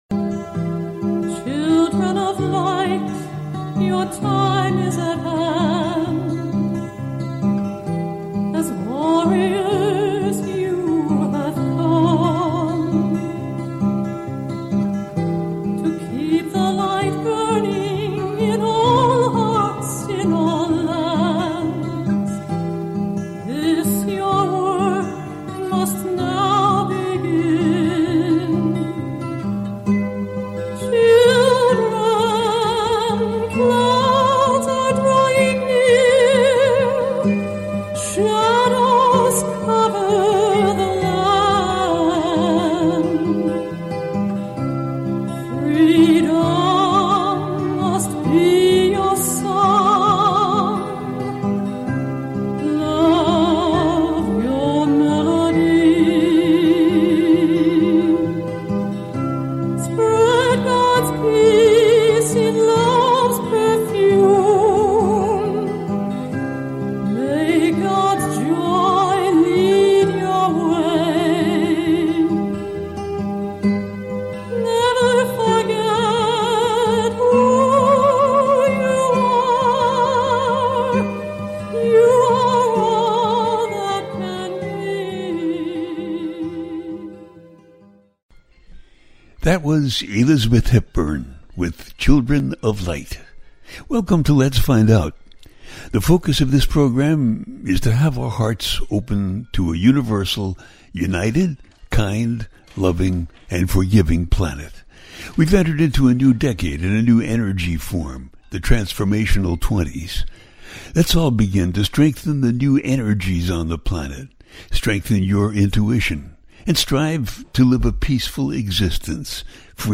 Fall Equinox- the First Wave of The 5D Ascension Timeline - A teaching show